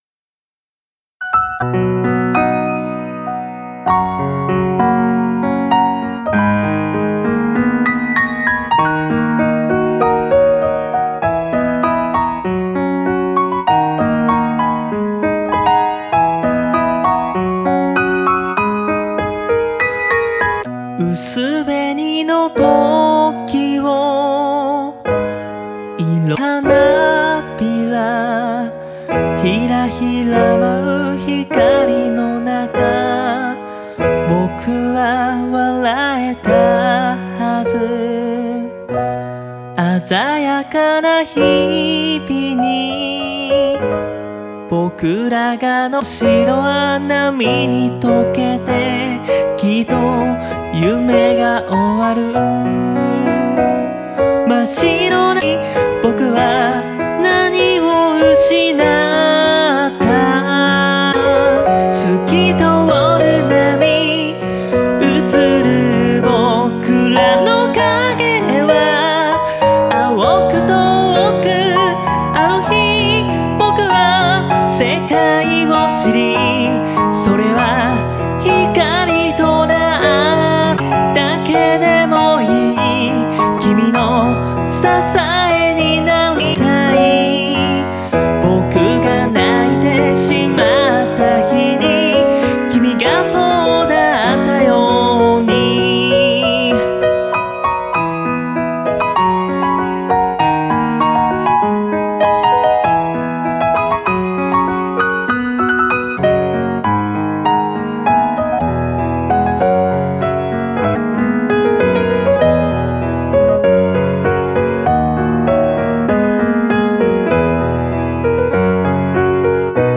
-piano ver.-